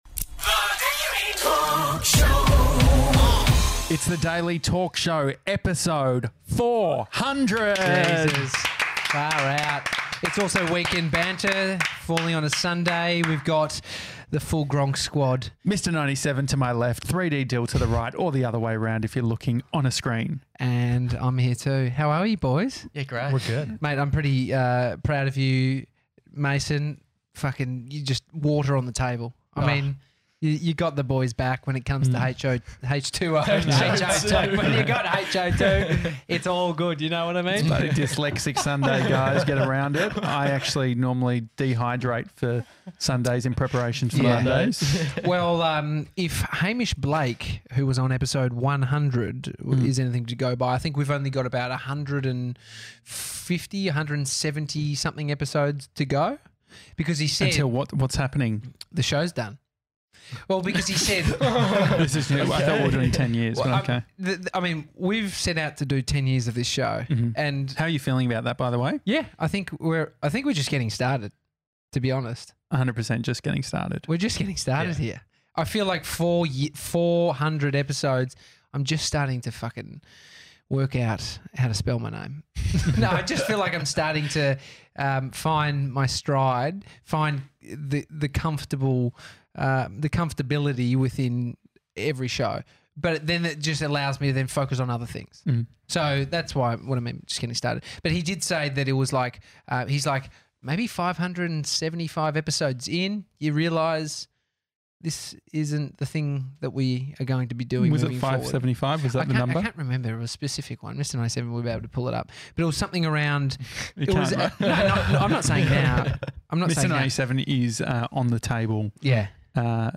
We've got the whole team on for Weekend Banter! We talk about our favourite experiences from the show, how far we've come and what we're excited for in the future.